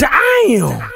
Tm8_Chant11.wav